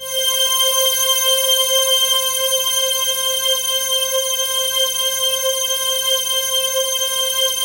PAD3  C5  -L.wav